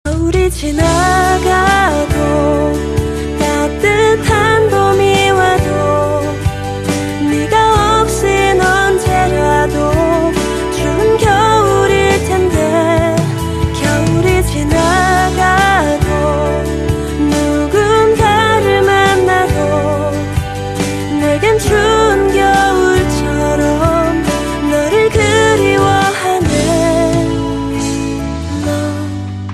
M4R铃声, MP3铃声, 日韩歌曲 64 首发日期：2018-05-15 10:40 星期二